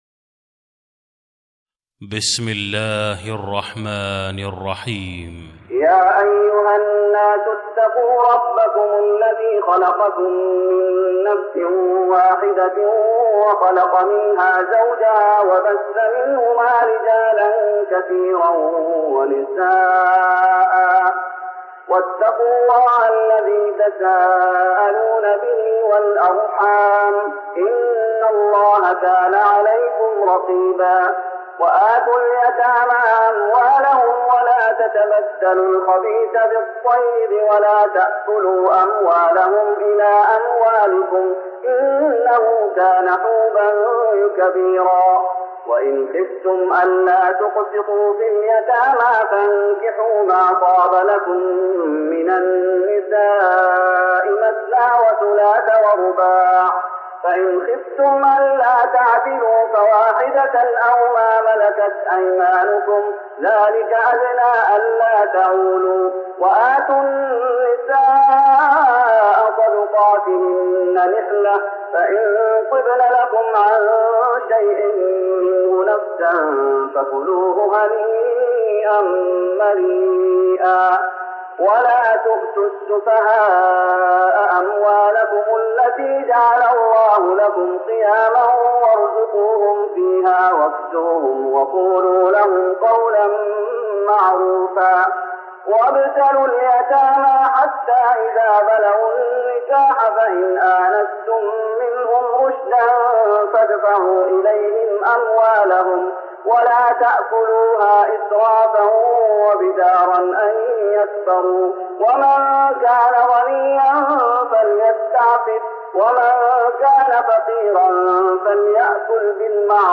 تراويح رمضان 1414هـ من سورة النساء (1-50) Taraweeh Ramadan 1414H from Surah An-Nisaa > تراويح الشيخ محمد أيوب بالنبوي 1414 🕌 > التراويح - تلاوات الحرمين